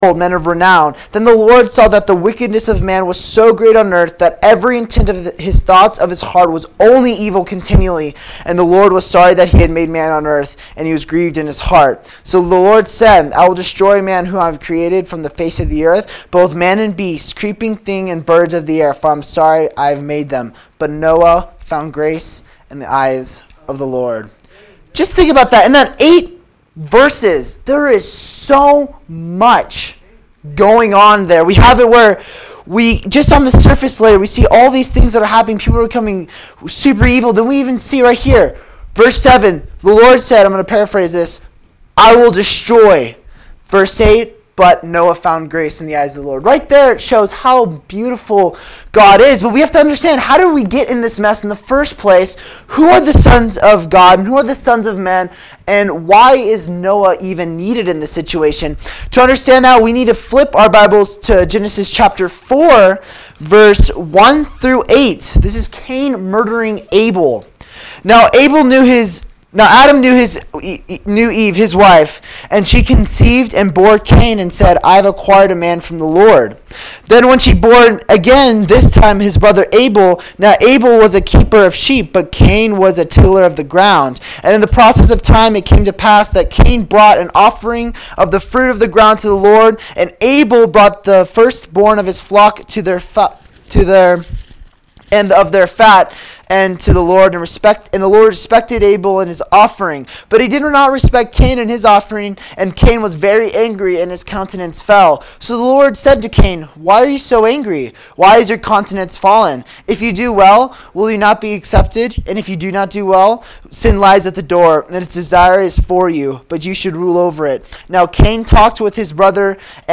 Sermons The Ark in the Last Days March 27